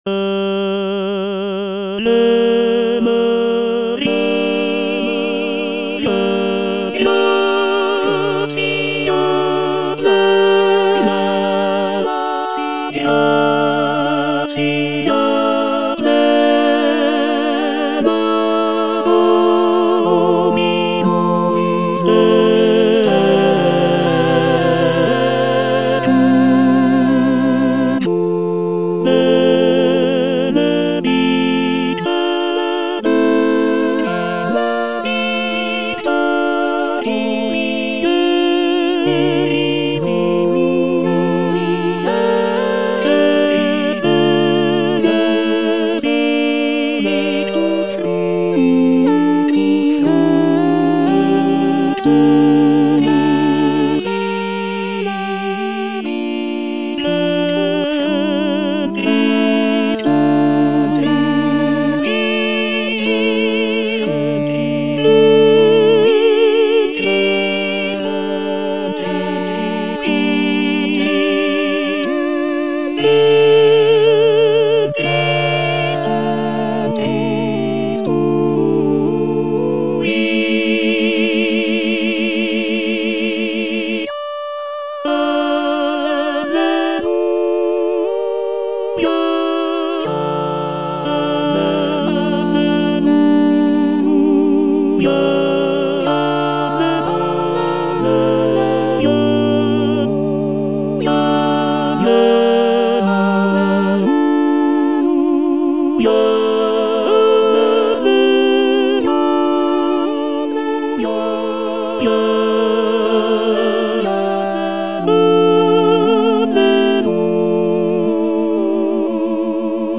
W. BYRD - AVE MARIA Tenor I (Emphasised voice and other voices) Ads stop: auto-stop
It is a polyphonic setting of the Latin prayer "Ave Maria" (Hail Mary).
The piece is known for its serene and contemplative atmosphere, with a sense of reverence and devotion.